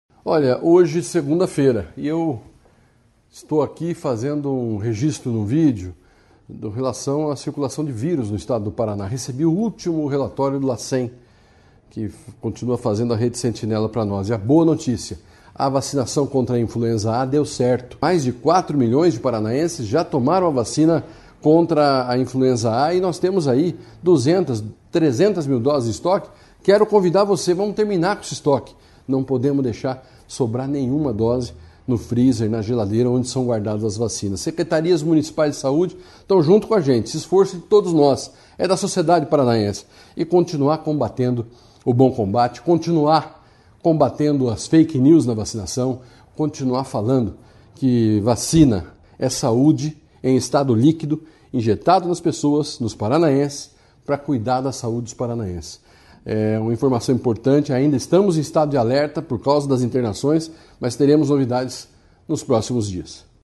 Sonora do secretário Estadual de Saúde, Beto Preto, sobre a diminuição da circulação do vírus da gripe no Paraná